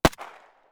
PistolClose.wav